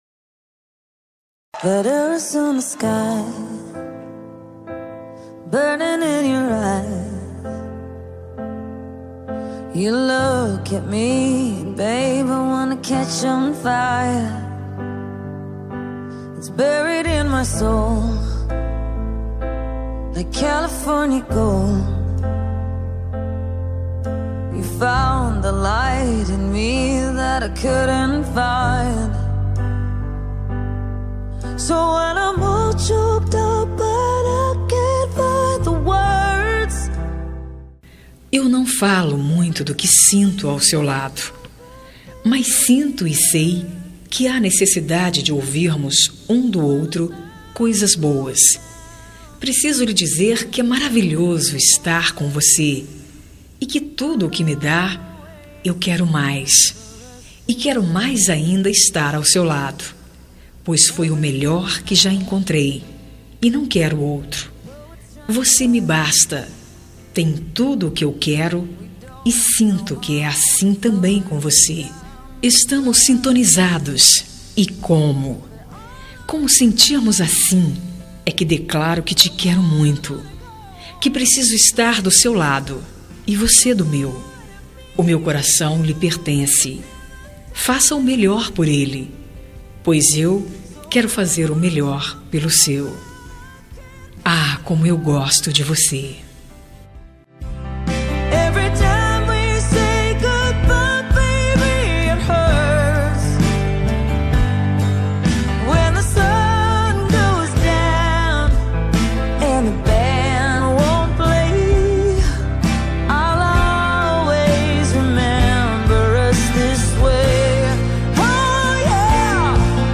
Telemensagem Ficante – Voz Feminina – Cód: 5449 – Linda
5449-ficante-fem-linda.m4a